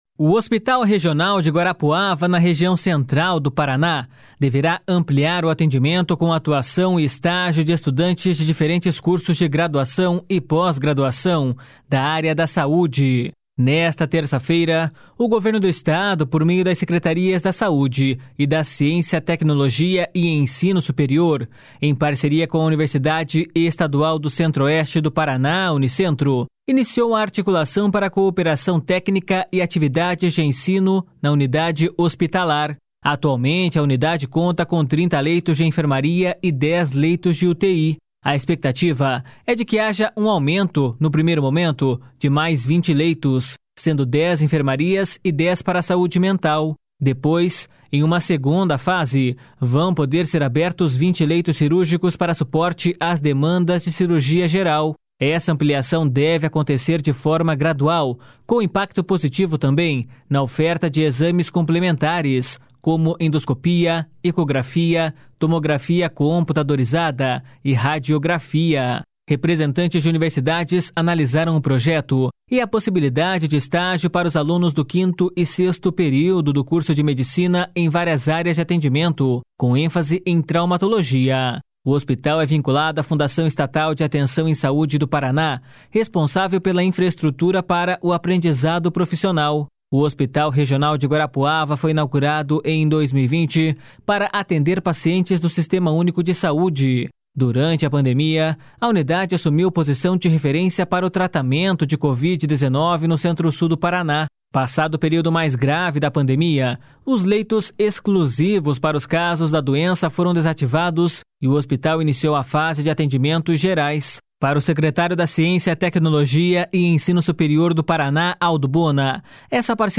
Para o secretário da Ciência, Tecnologia e Ensino Superior do Paraná, Aldo Bona, essa parceria institucional é fundamental para a estruturação do hospital.// SONORA ALDO BONA.//